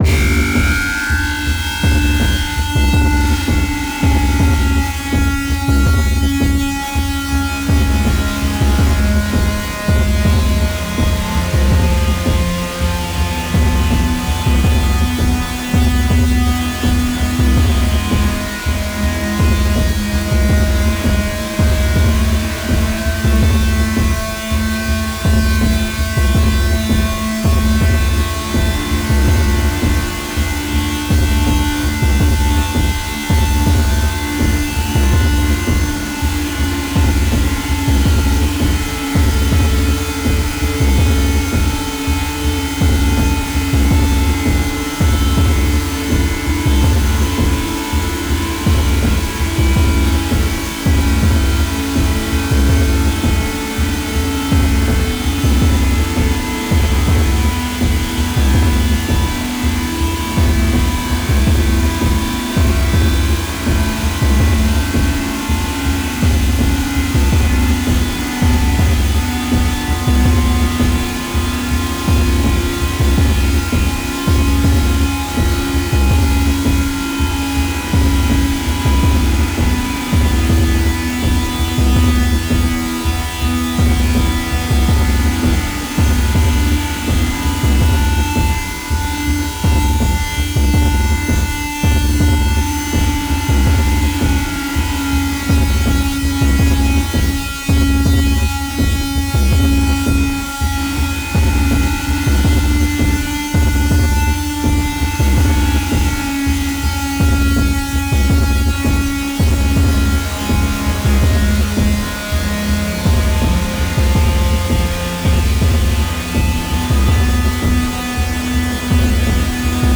大地を轟かす太く重い狼の"鼓動"。
天と地を結ぶ霊獣の為の、美しく力強い祝祭曲。